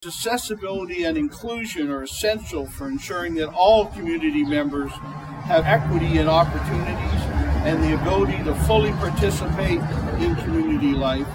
The week was marked with a flag raising ceremony and proclamation reading by Preston Monday morning—underscoring the city’s ongoing commitment to breaking down barriers for people with disabilities.